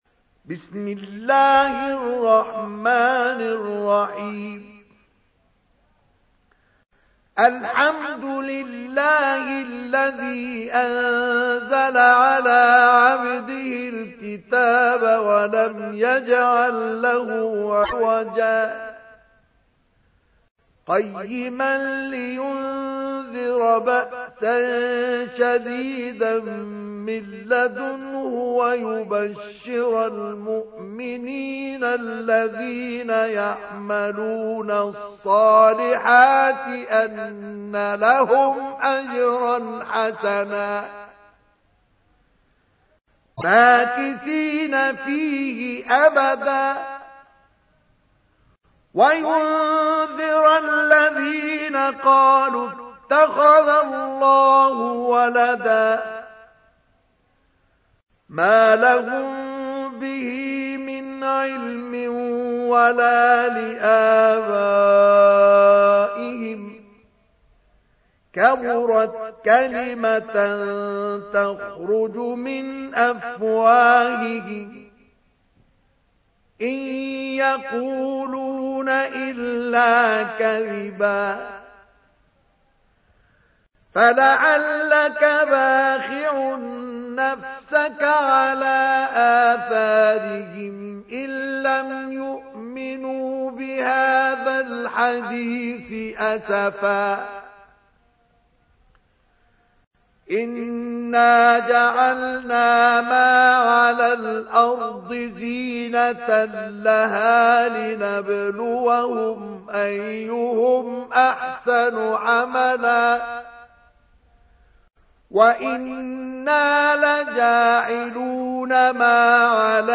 تحميل : 18. سورة الكهف / القارئ مصطفى اسماعيل / القرآن الكريم / موقع يا حسين